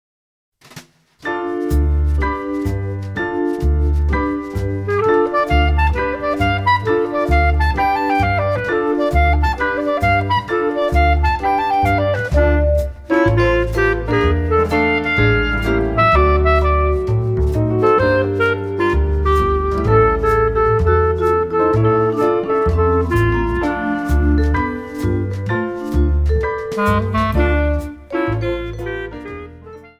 Vibraphone
Piano
Bass
Drums